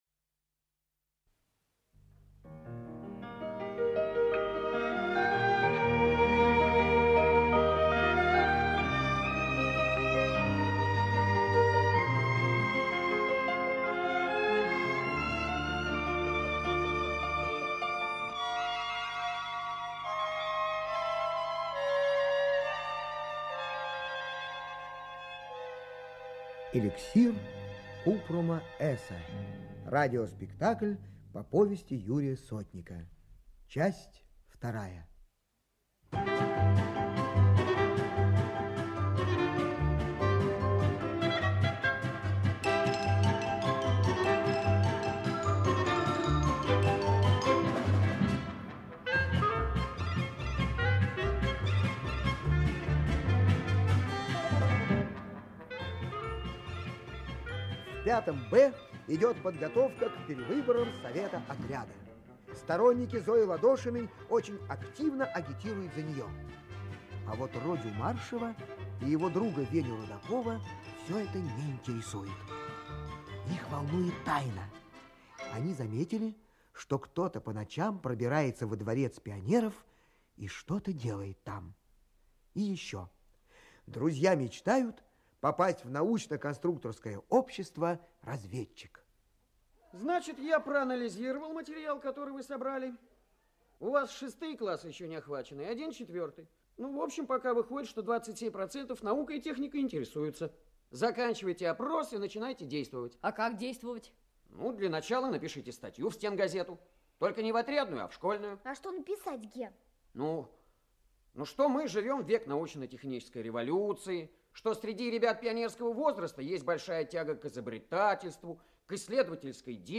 Аудиокнига Эликсир Купрума Эса. Часть 2 | Библиотека аудиокниг
Часть 2 Автор Юрий Сотник Читает аудиокнигу Лев Дуров.